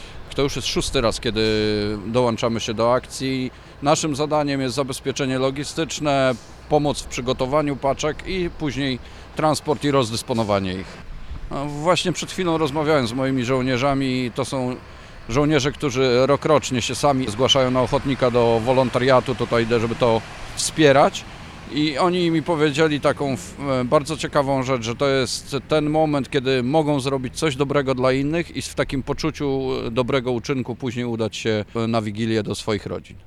O zadaniach jakie mają wojskowi w czasie tej akcji oraz o emocjach jakie to w nich wywołuje mówi płk. Edward Chyła, dowódca 16. Brygady Obrony Terytorialnej.